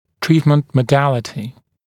[‘triːtmənt mə’dælətɪ][‘три:тмэнт мэ’дэлэти]способ лечения